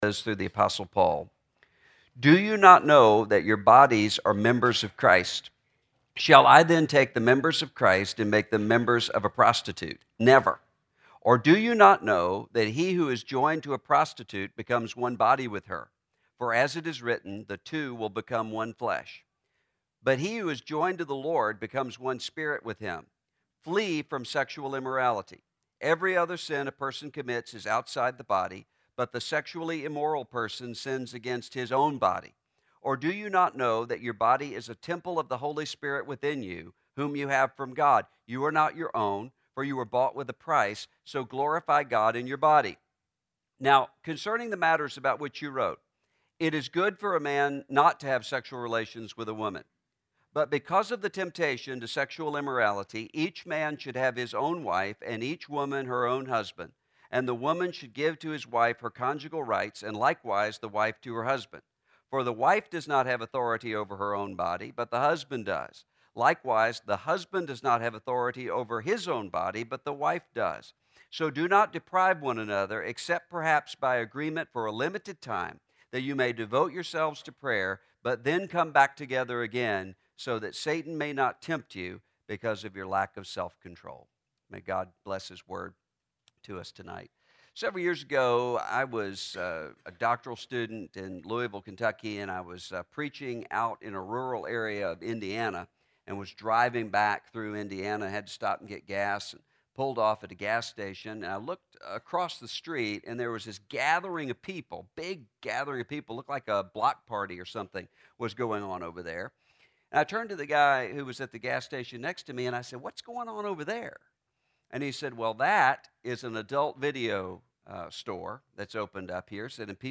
Guest Speaker – Russell Moore (Sexual Insanity, Religious Liberty & Future of Church in America)
Sermon